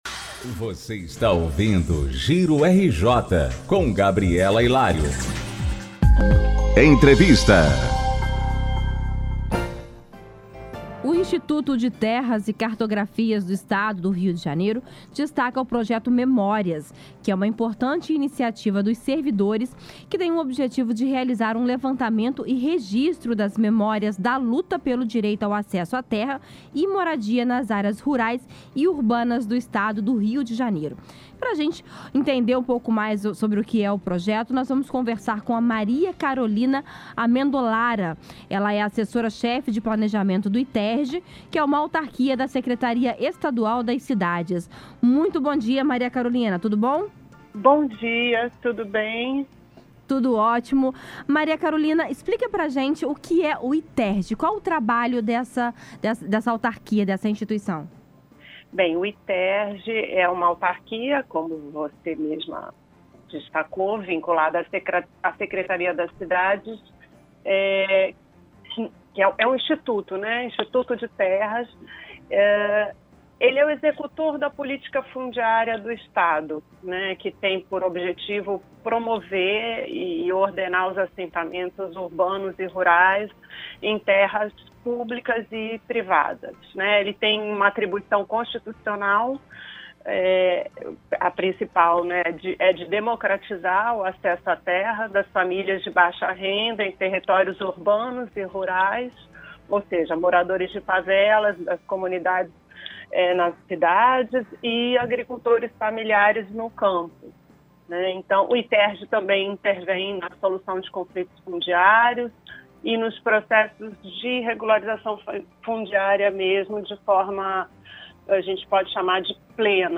Confira com foi a entrevista sobre o tema na rádio Roquette Pinto